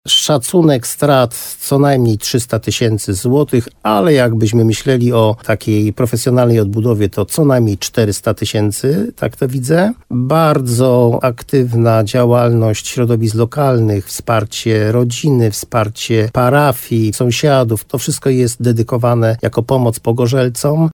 Jak mówi wójt gminy Moszczenica, Jerzy Wałęga, wciąż jest wiele do zrobienia, żeby rodzina mogła znów zamieszkać w swoim domu.